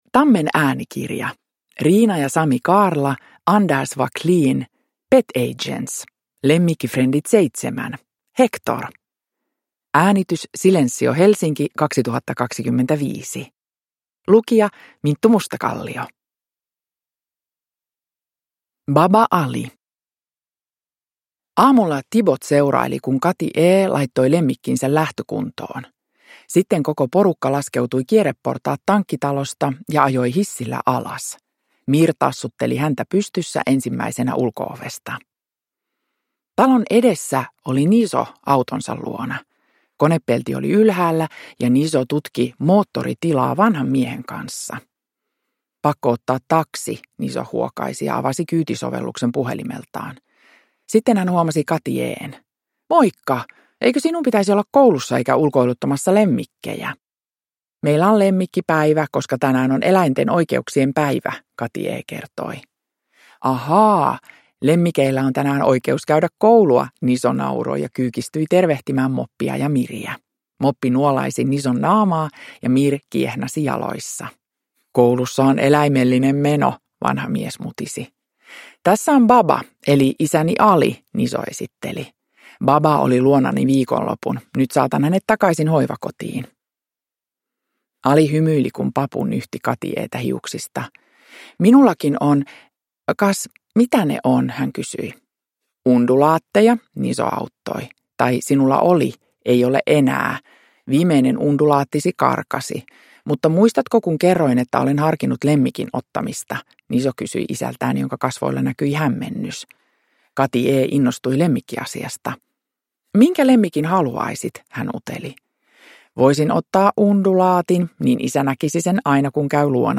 Hector. Lemmikkifrendit 7 – Ljudbok
Uppläsare: Minttu Mustakallio